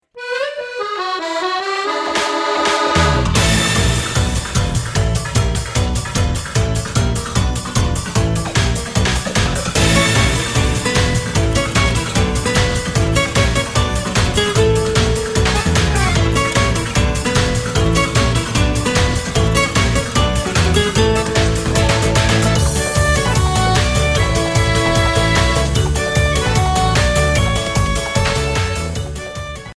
The remix
Fade-out added